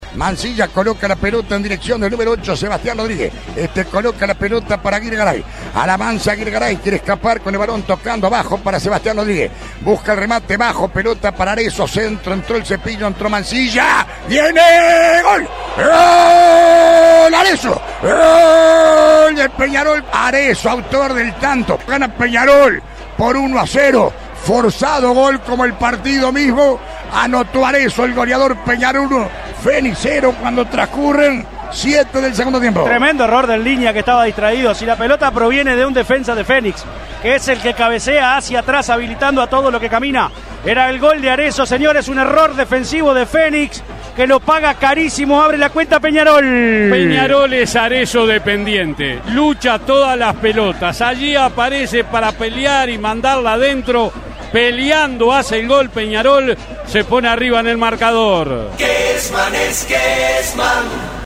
ESCUCHÁ ACÁ EL RELATO DE GOL DE ALBERTO KESMAN